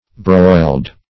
Broiled - definition of Broiled - synonyms, pronunciation, spelling from Free Dictionary
broiled \broiled\ adj.